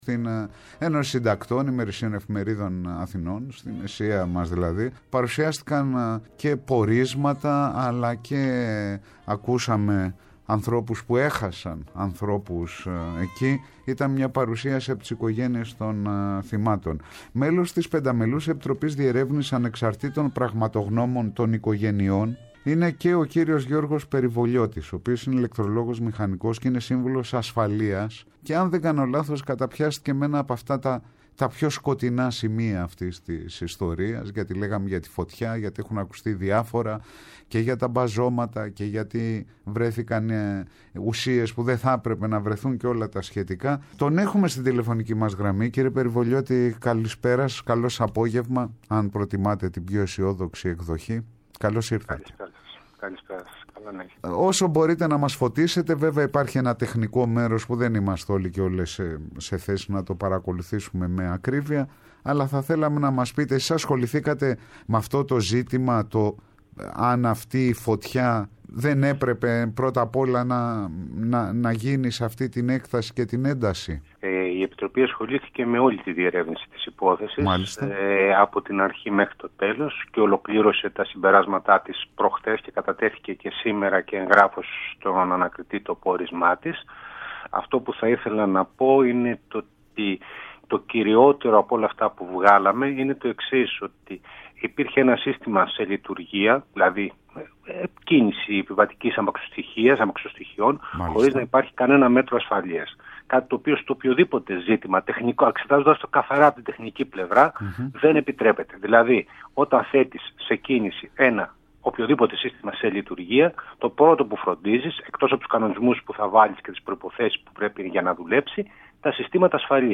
Οπως λέει στο Πρώτο Πρόγραμμα, την εκπομπή “Απολύτως Σχετικό”